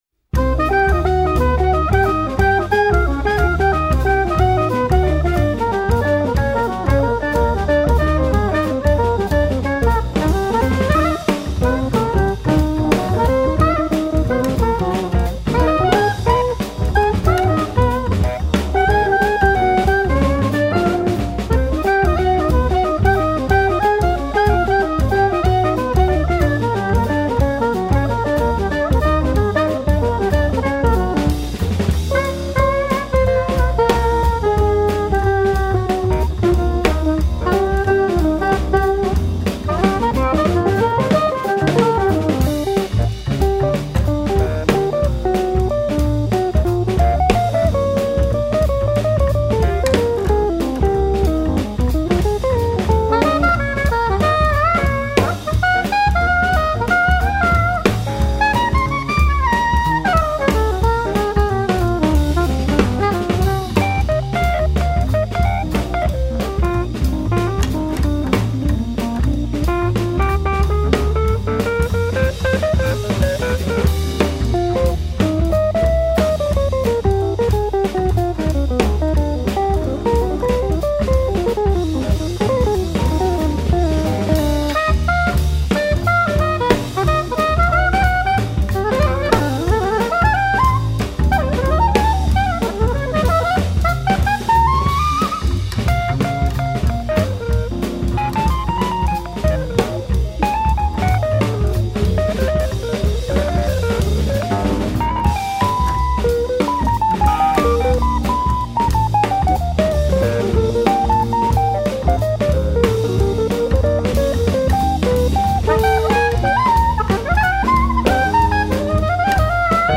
soprano and tenor saxofones, flute
piano and fender rhodes
guitar
double bass
drums
Jazz